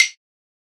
soft-hitnormal.ogg